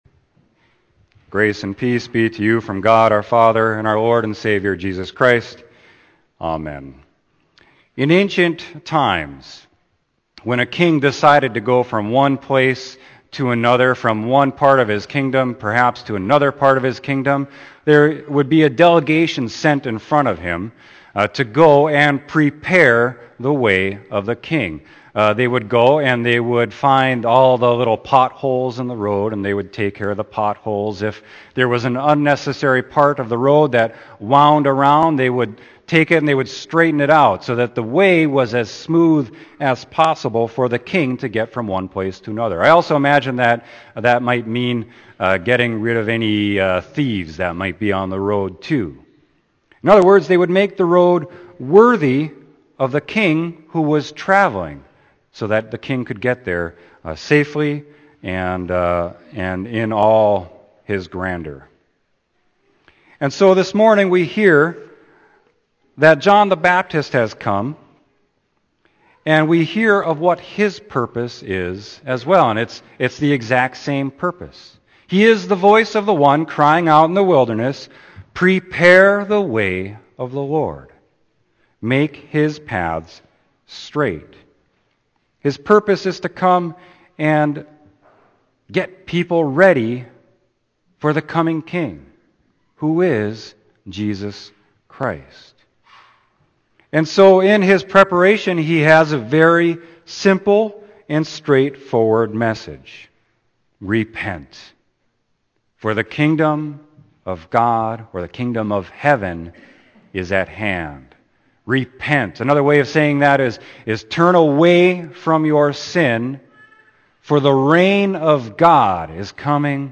Sermon: Matthew 3:1-12